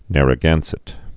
(nărə-gănsĭt)